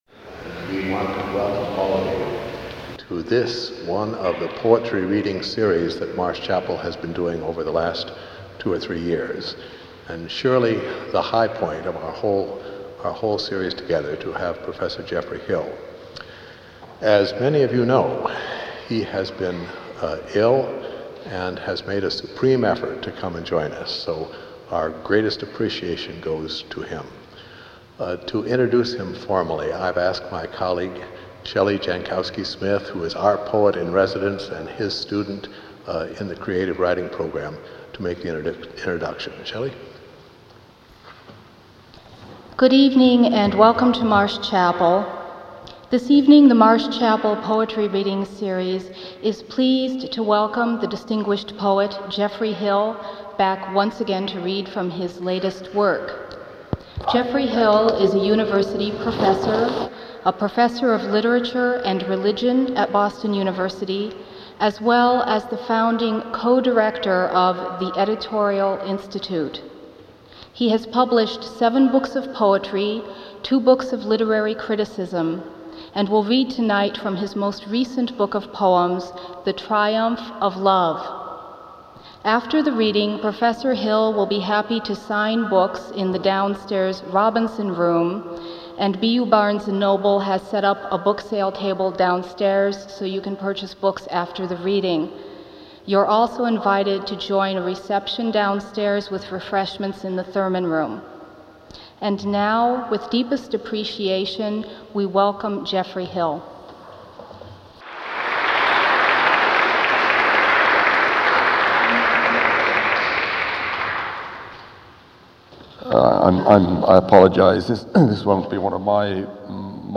Poetry Reading by Geoffrey Hill
Professor Geoffrey Hill was Professor of Literature and Religion at Boston University, and had just released his 1998 book, "The Triumph of Love." He spoke as part of the Marsh Chapel Poetry Reading series.